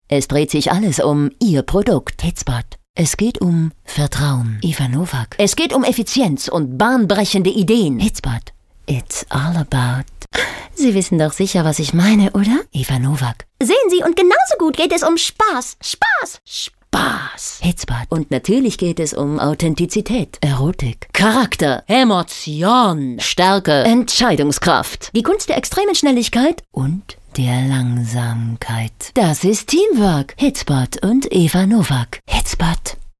wienerisch
Sprechprobe: Werbung (Muttersprache):